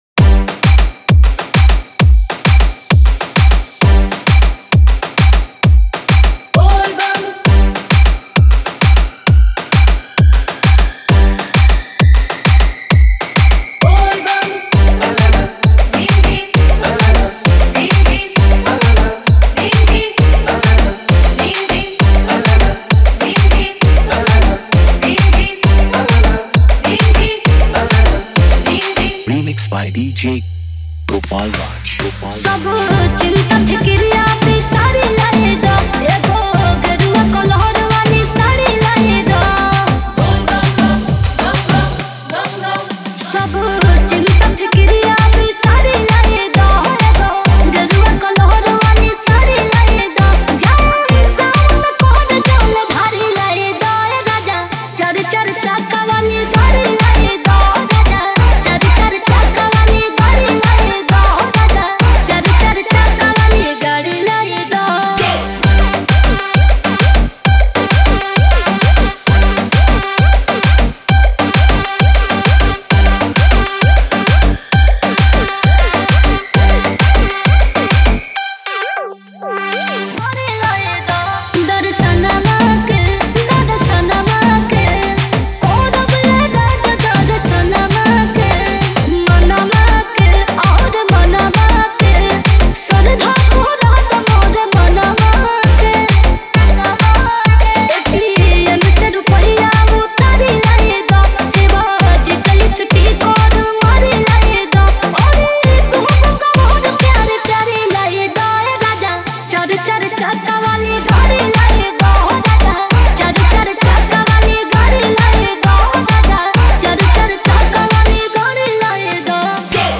Category : Bhakti DJ Remix Songs